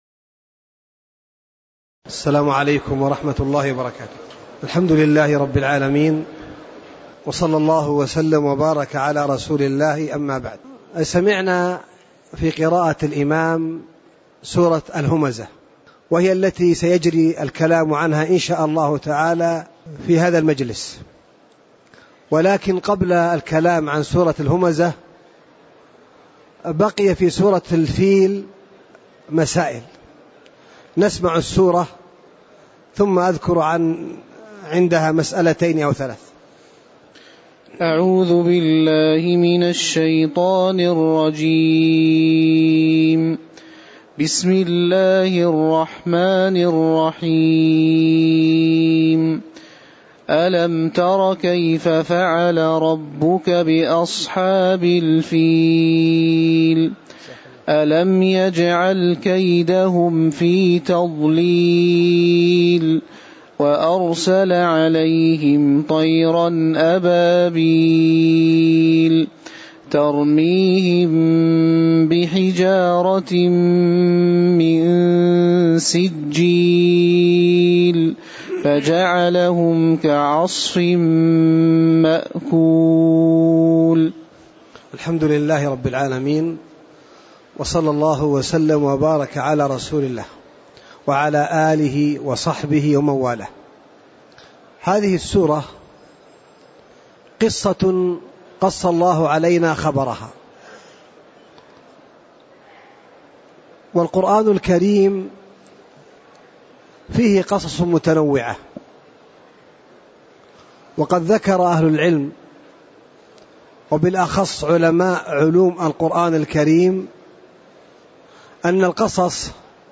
تاريخ النشر ٧ جمادى الآخرة ١٤٣٧ هـ المكان: المسجد النبوي الشيخ